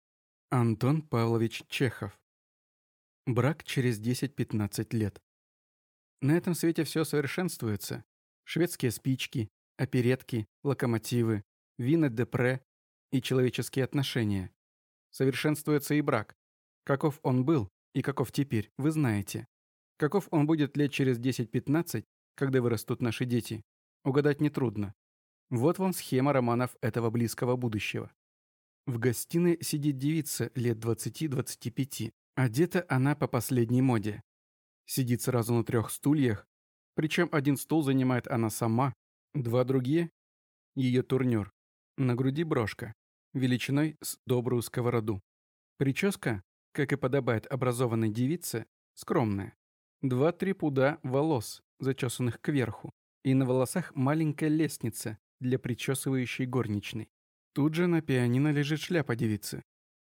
Аудиокнига Брак через 10–15 лет | Библиотека аудиокниг